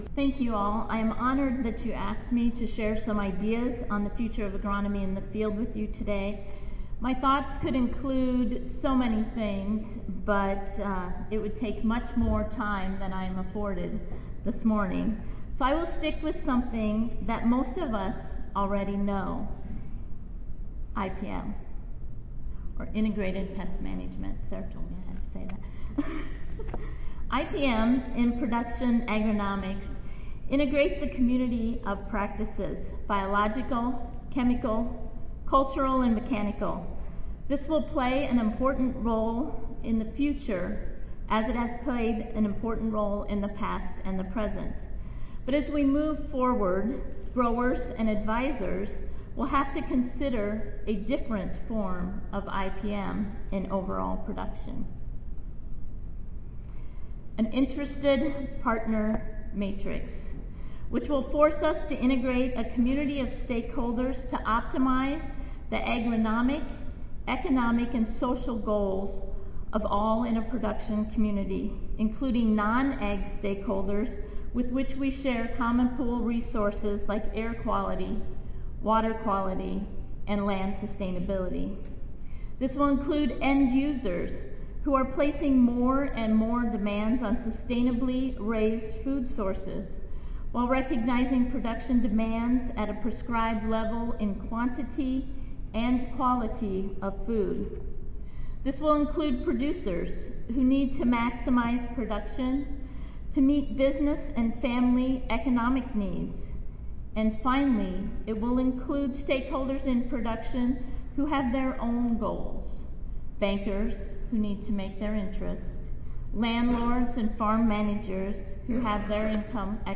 Keynote/Plenary Sessions
Audio File Recorded Presentation